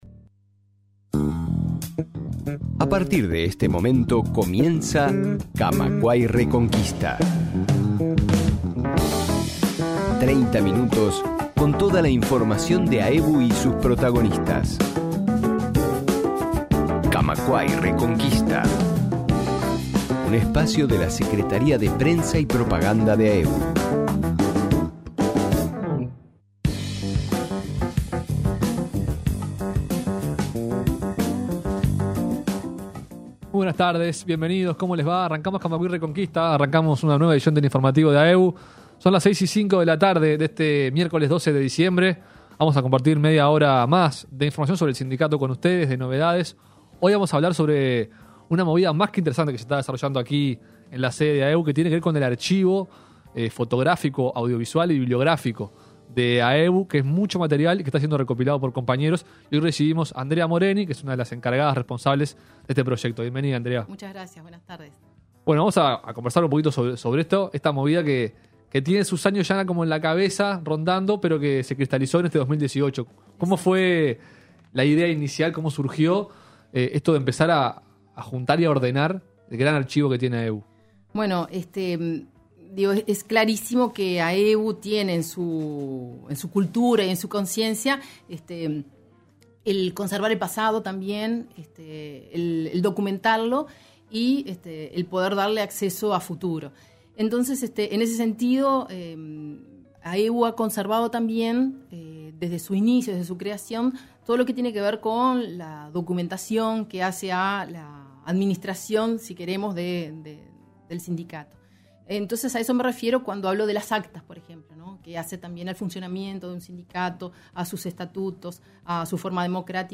En entrevista con Camacuá y Reconquista